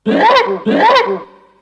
One of Donkey Kong's voice clips in Mario Party 5